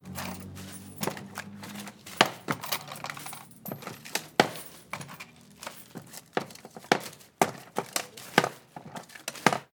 Contar el dinero de una caja registradora
caja registradora
Sonidos: Acciones humanas